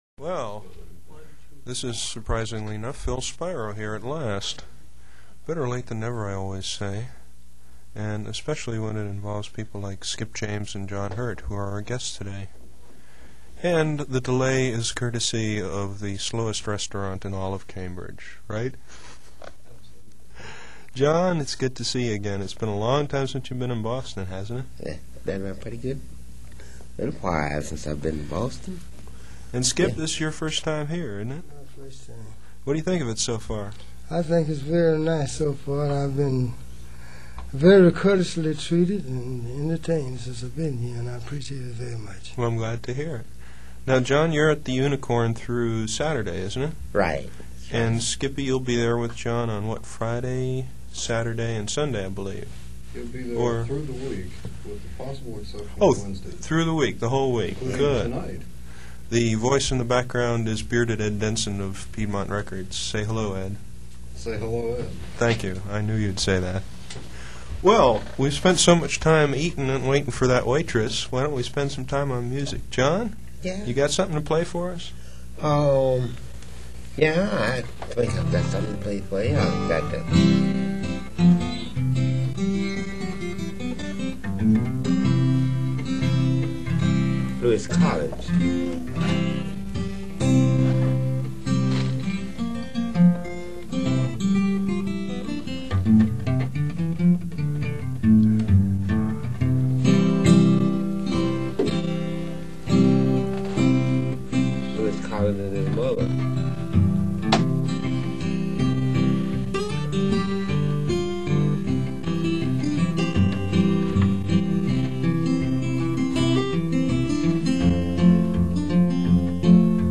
a taste of rural blues